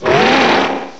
sovereignx/sound/direct_sound_samples/cries/wyrdeer.aif at master